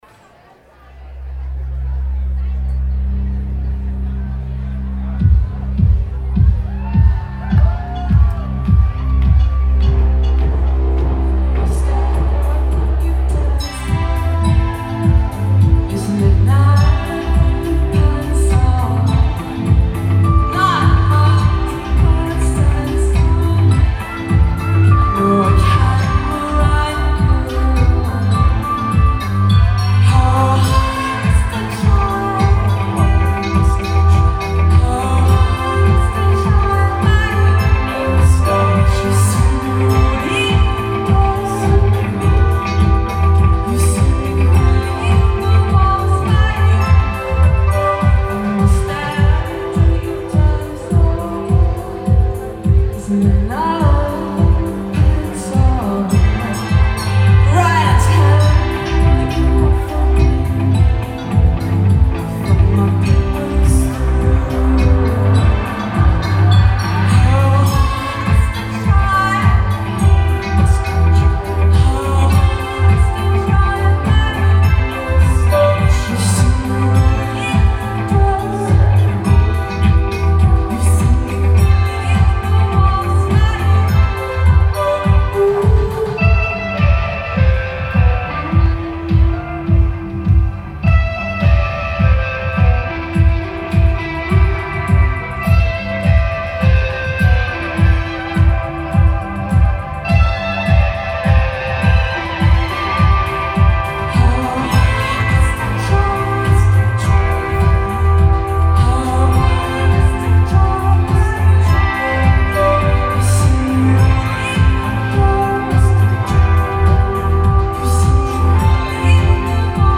male/female vocal format
Here is a recent live take on their lead single.